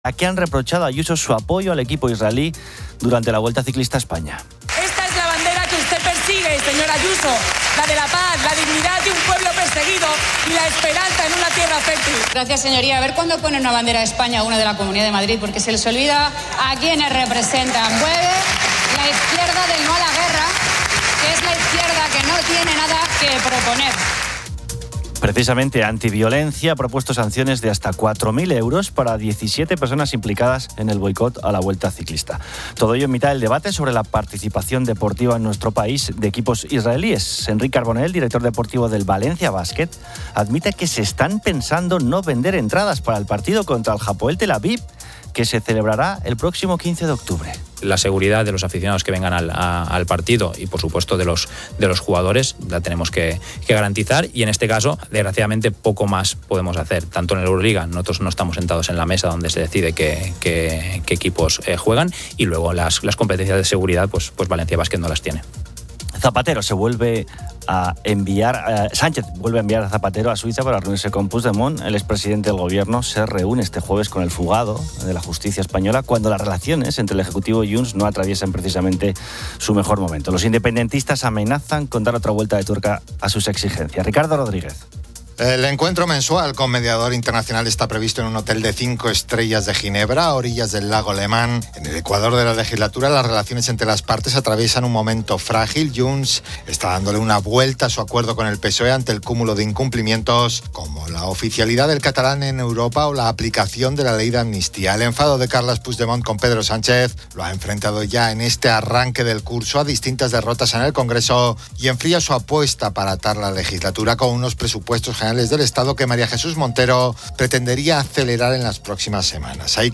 Un reportaje de COPE destaca la recuperación de un colegio en Catarroja un año después de ser afectado por una DANA, con testimonios de alumnos y personal.